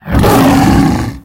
48d440e14c Divergent / mods / Soundscape Overhaul / gamedata / sounds / monsters / bloodsucker / attack_hit_3.ogg 13 KiB (Stored with Git LFS) Raw History
attack_hit_3.ogg